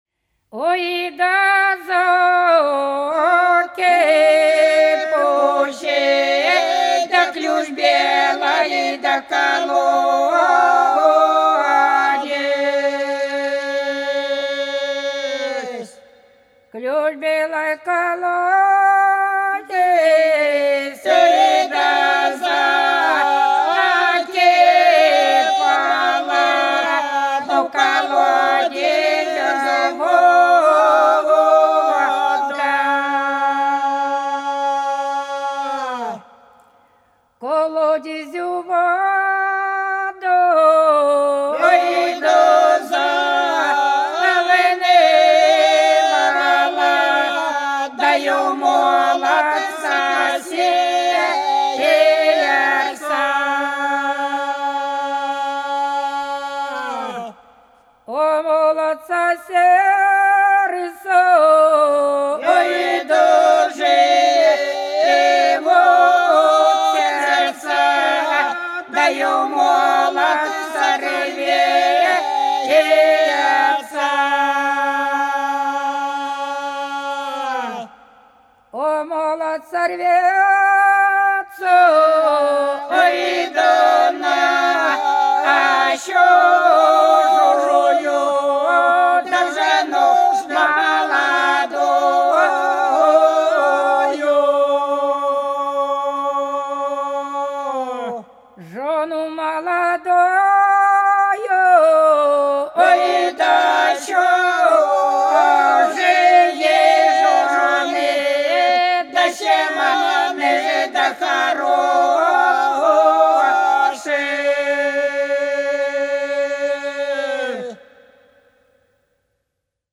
По-над садом, садом дорожка лежала Ой да закипучий да ключ белый колодезь - постовая (с.Плёхово, Курская область)